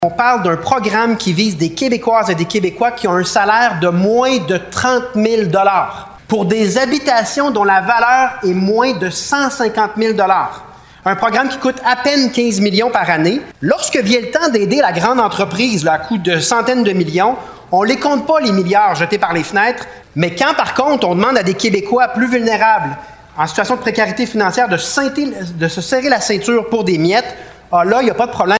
Le chef du Parti québécois, Paul Saint-Pierre Plamondon, s’implique dans le dossier de Réno Régions et intervient à ce sujet à l’Assemblée nationale.
Prenant la parole au parlement, le chef péquiste a sévèrement dénoncé l’abolition du programme Réno Région par la CAQ, dans le dernier budget. Il a interpellé le gouvernement en tandem avec la députée de Terrebonne, Catherine Gentilcore, lors de la période des questions.